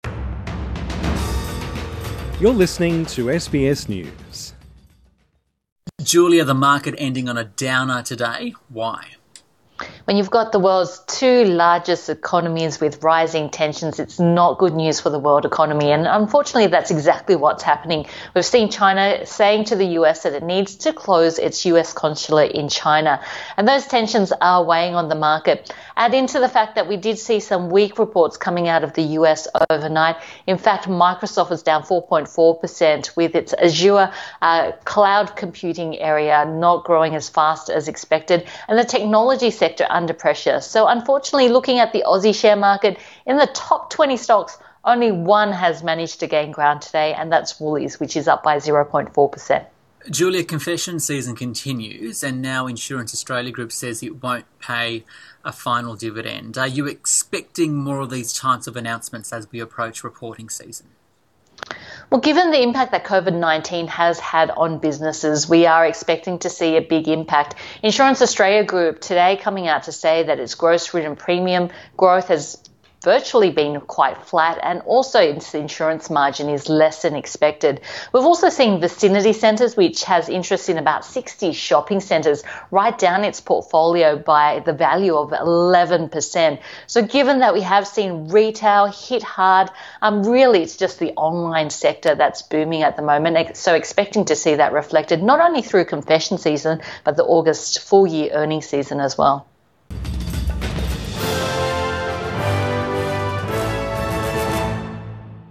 A market wrap-up